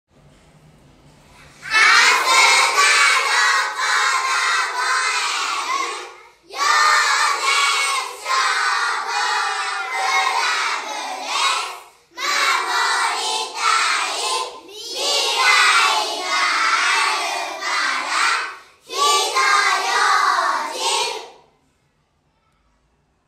「火災予防運動」や「歳末警戒」の一環で、管内の子ども達（幼年消防クラブ員）が録音した音声を消防車から流し、パトロールをします。
子ども達は、かわいい元気いっぱいの声で「守りたい 未来があるから 火の用心」と音声を録音して協力して頂きました。
広報音声（順不同）